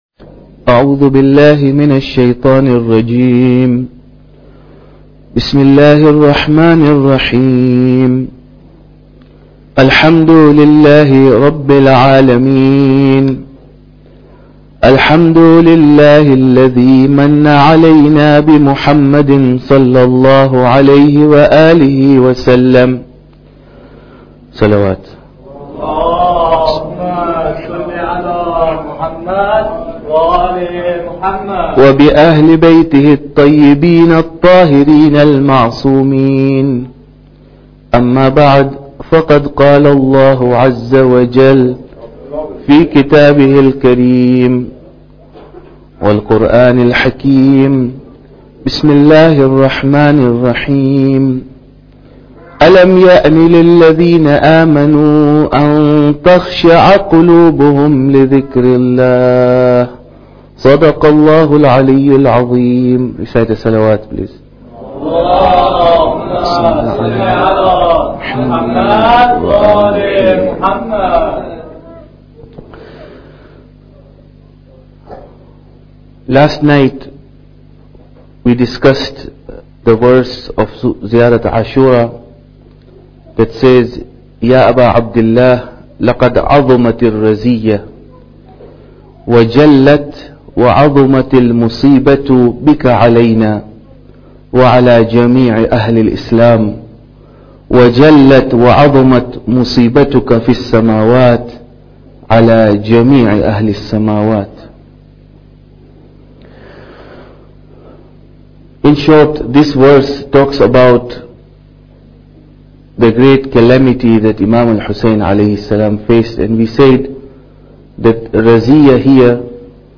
Muharram Lecture 9